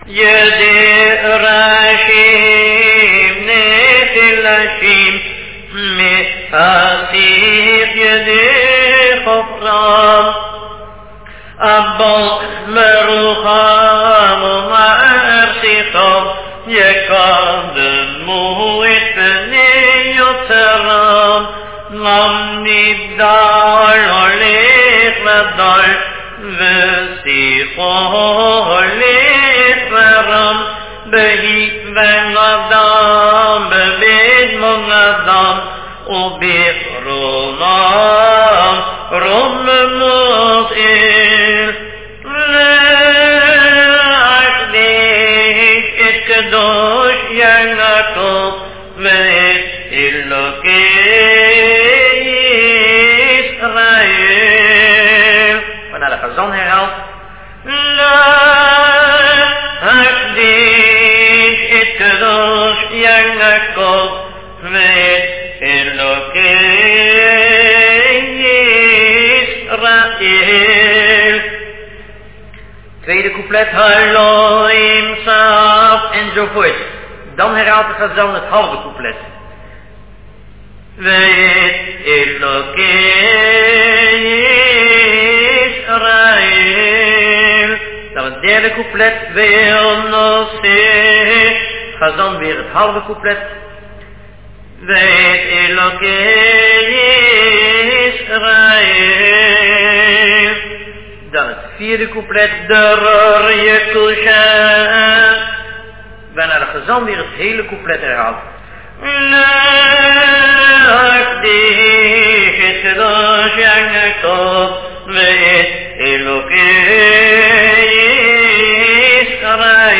Sung.